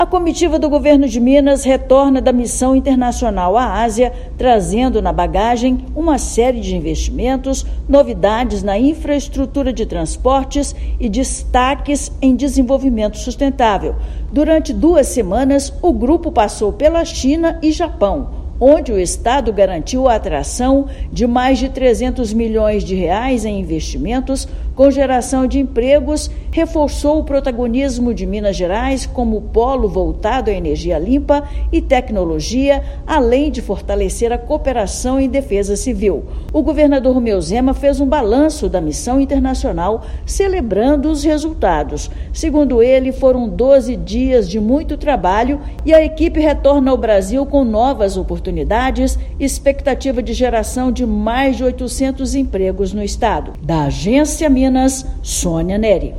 Comitiva do Estado viu a montagem dos novos trens do Metrô da RMBH, conheceu novas tecnologias, estratégias para prevenção de desastres e enfatizou potencialidades de Minas para receber diversas iniciativas. Ouça matéria de rádio.